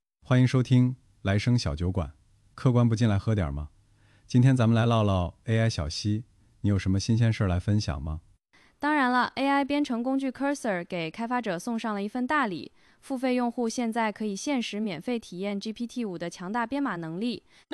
indexTTS.wav